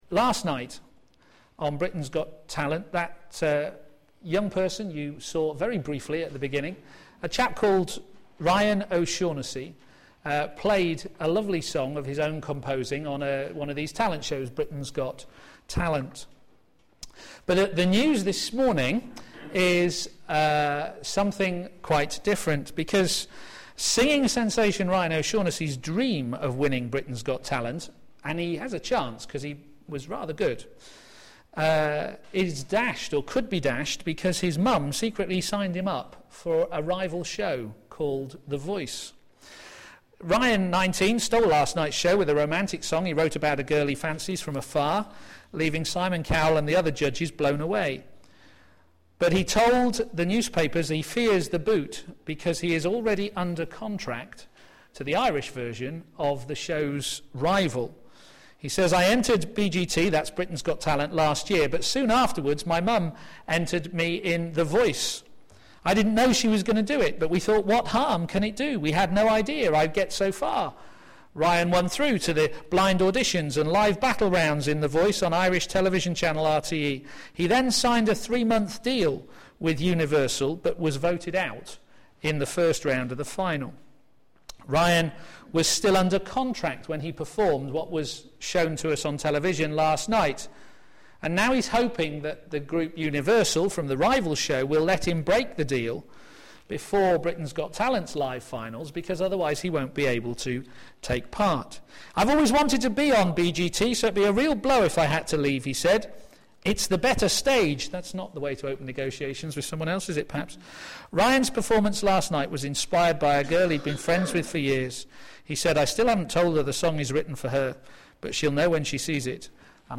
Media for a.m. Service
Series: John on Jesus Theme: Triumphal Entry - Impending Death Sermon